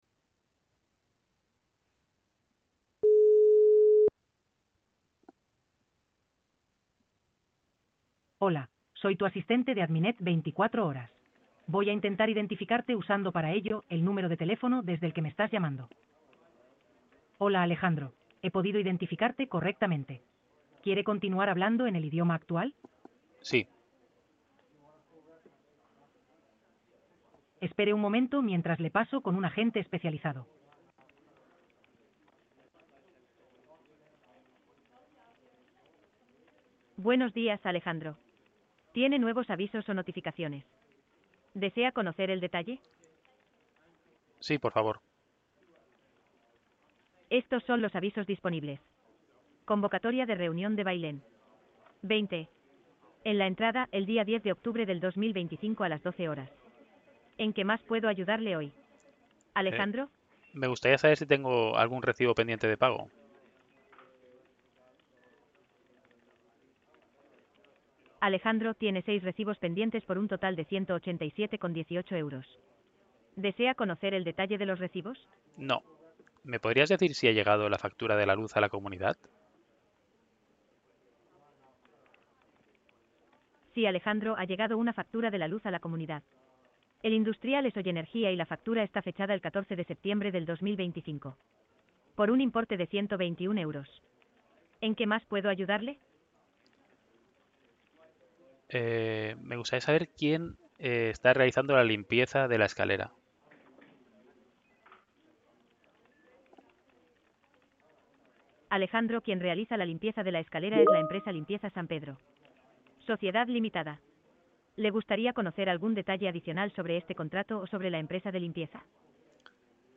Ejemplo de una llamada con el asistente telefónico